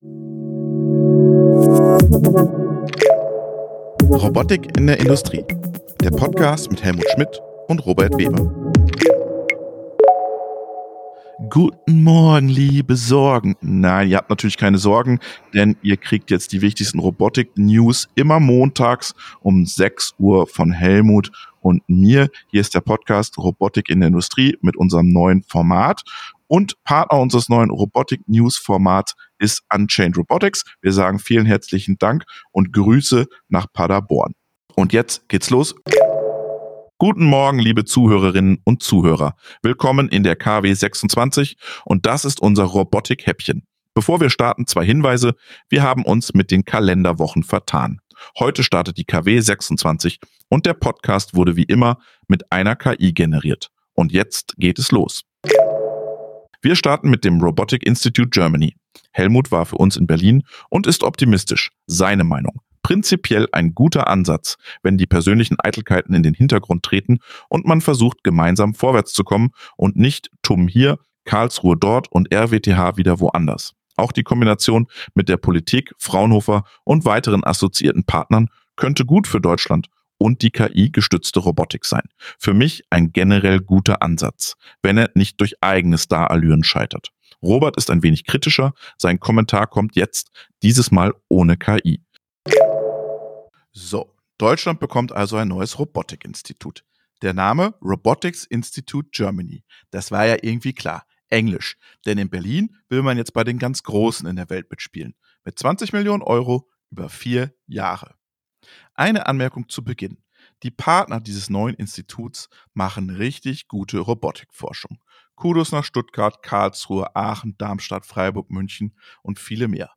Unser Newshäppchen für die Fahrt zur Arbeit. Immer Montags gibt es die Robotik News - mit-recherchiert, geschrieben und gesprochen mit und von einer KI.